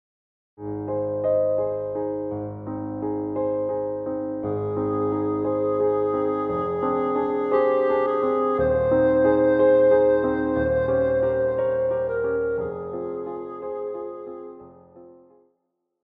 古典
聲樂-女
鋼琴
婚禮歌曲,經典曲目,聖歌,電視／電影配樂
聲樂與伴奏